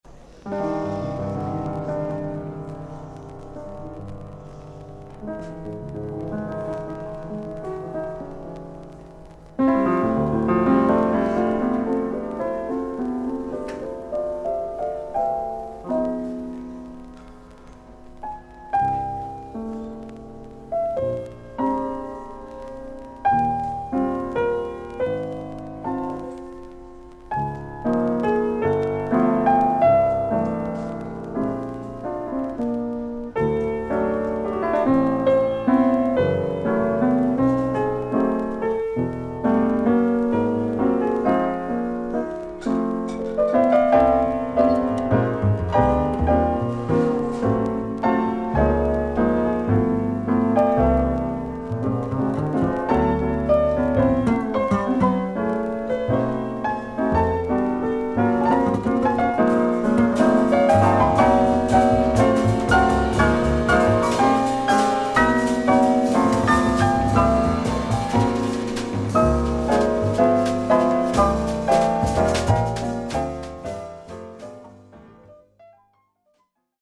少々軽いパチノイズの箇所あり。少々サーフィス・ノイズあり。クリアな音です。
ジャズ・ピアニスト。